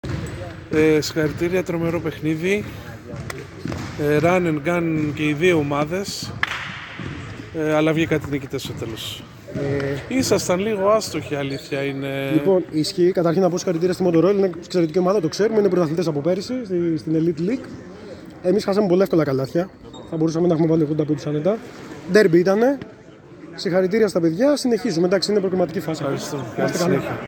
GAMES INTERVIEWS
Παίκτης Grant Thornton